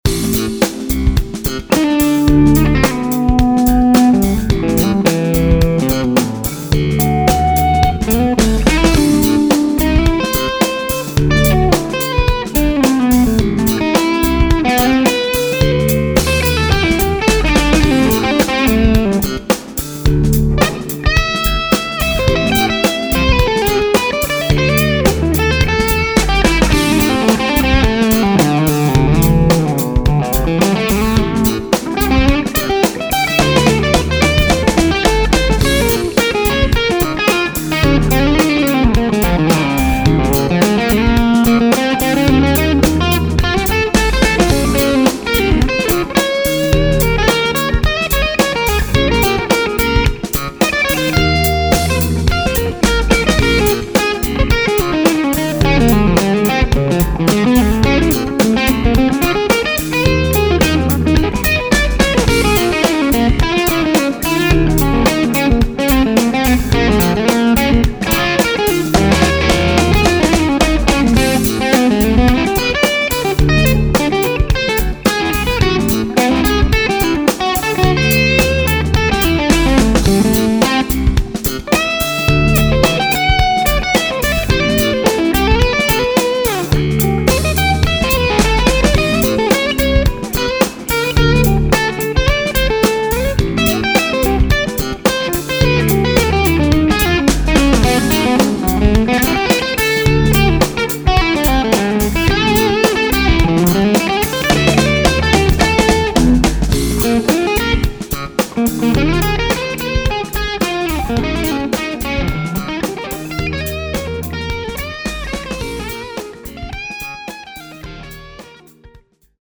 A little departure as I also changed the preamp tubes to Chinese and I used a Sennheiser e609 mic instead of my usual SM57. G1265 speakers btw.
-BM poweramp, Skyline TS w/special tweaks.
A little too fat for my taste - but you definitely don't need to apologize for the playing.
Sounds nice and smooth, but a little dampened...could use a bit more of the sparkle.